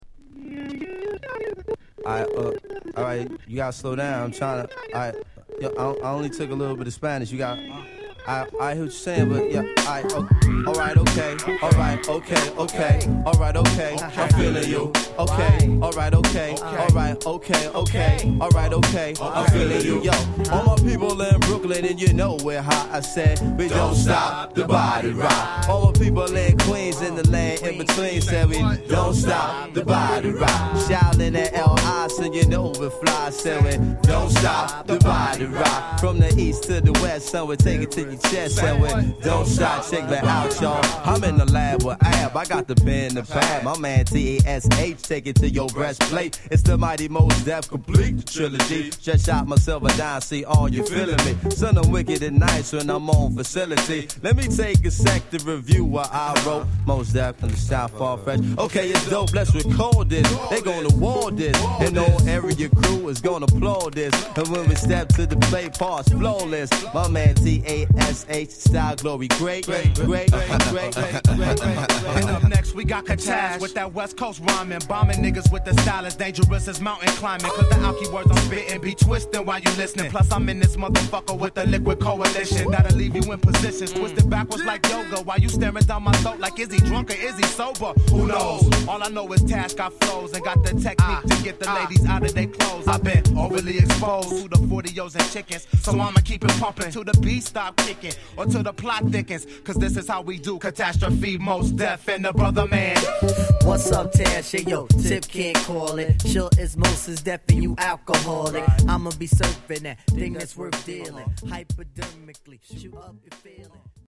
オールドスクールの名ラインをもじったフレーズが随所に織り込まれるのも流石！